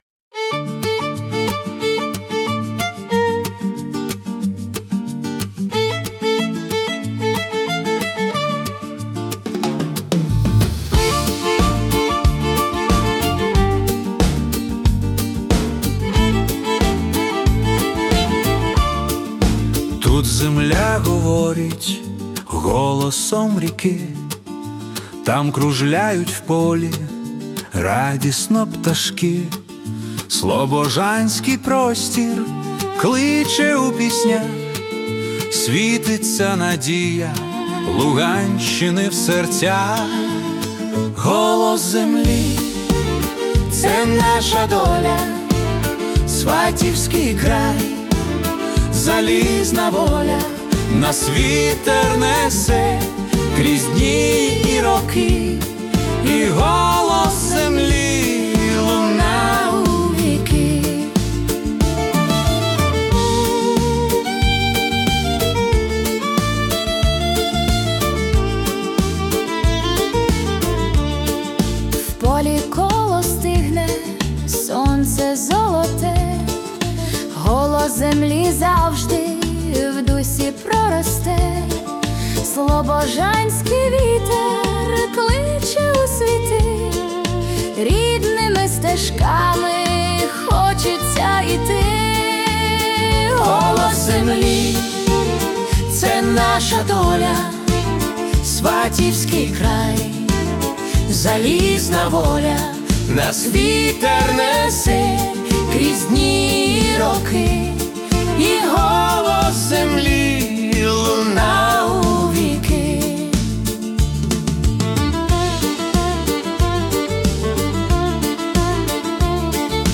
Folk-Pop / Native Soul
це світла і піднесена композиція у стилі Folk-Pop (92 BPM).